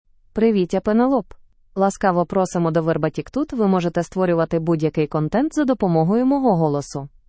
Penelope — Female Ukrainian AI voice
Penelope is a female AI voice for Ukrainian (Ukraine).
Voice sample
Listen to Penelope's female Ukrainian voice.
Female
Penelope delivers clear pronunciation with authentic Ukraine Ukrainian intonation, making your content sound professionally produced.